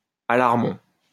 Allarmont (French pronunciation: [alaʁmɔ̃]